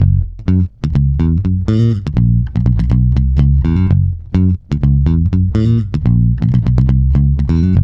-MM BOOG F#.wav